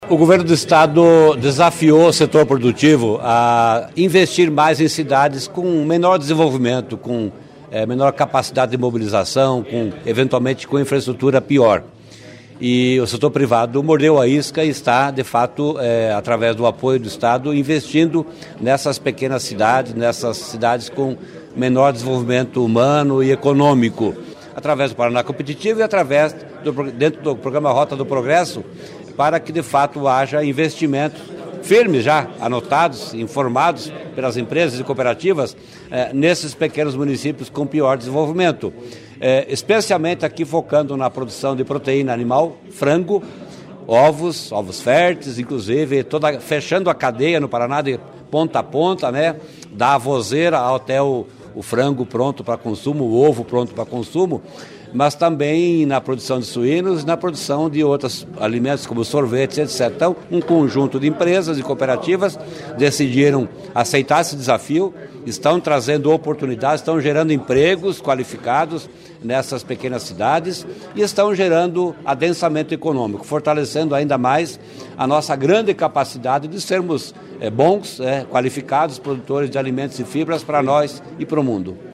Sonora do secretário Estadual da Fazenda, Norberto Ortigara, sobre a atração de investimentos pelo Rota do Progresso para cinco cidades